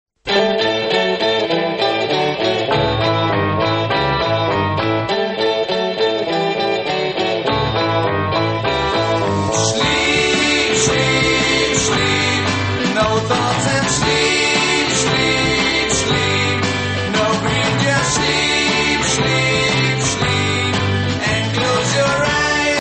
Beide zwart/wit en van nederlandse makelij.